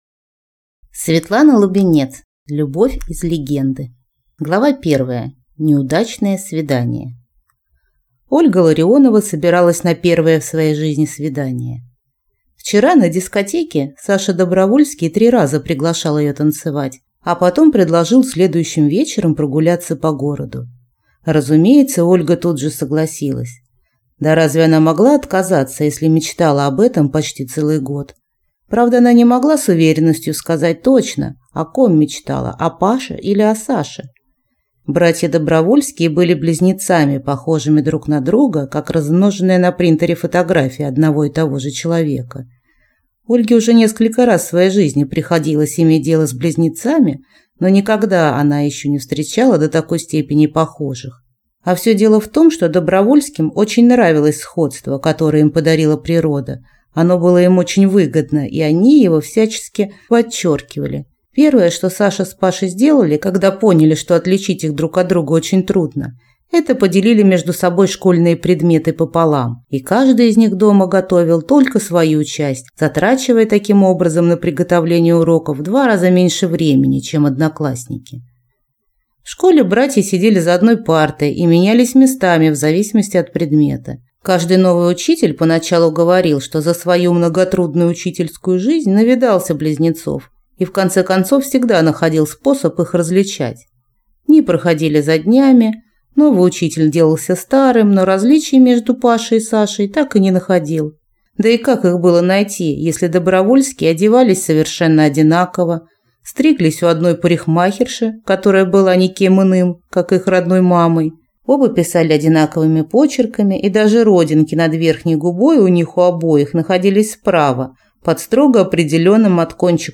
Аудиокнига Любовь из легенды | Библиотека аудиокниг
Прослушать и бесплатно скачать фрагмент аудиокниги